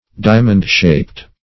Diamond-shaped \Di"a*mond-shaped`\, a. Shaped like a diamond or rhombus.